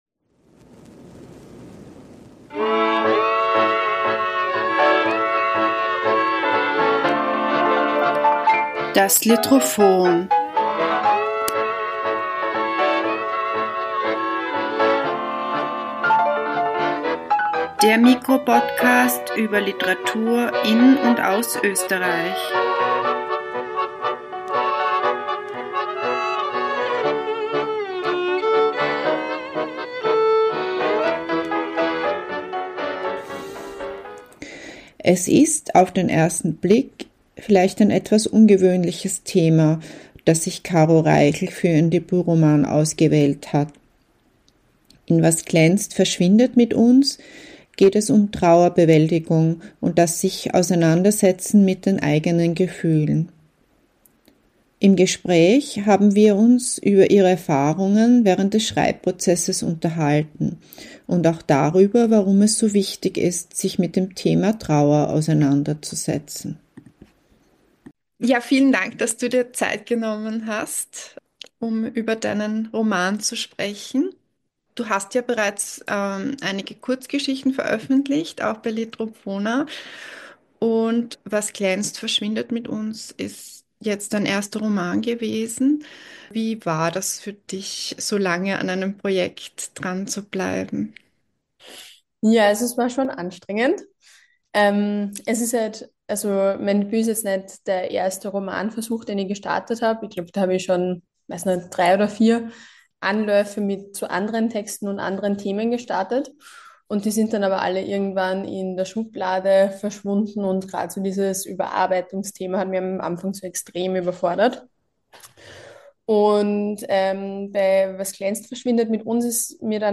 Im Gespräch haben wir uns über ihre Erfahrungen während des Schreibprozesses unterhalten und auch darüber, warum es so wichtig ist, sich mit dem Thema Trauer auseinanderzusetzen.